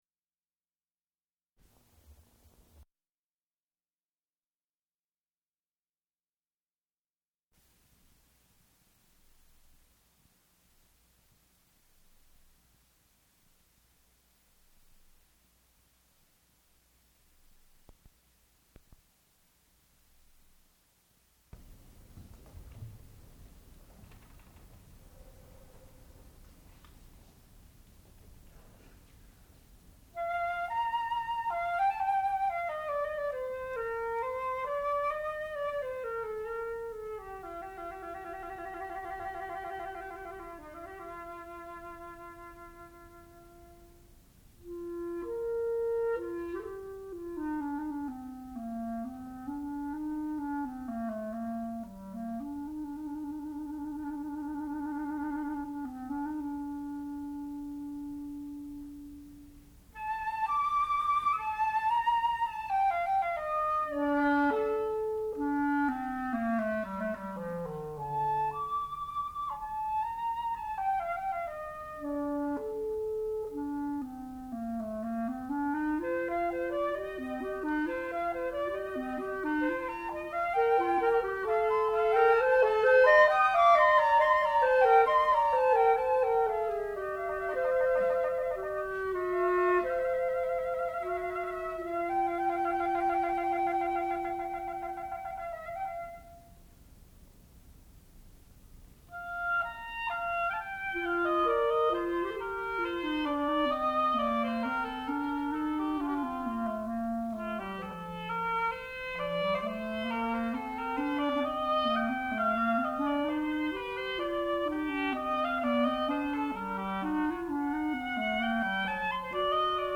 sound recording-musical
classical music
oboe
clarinet
flute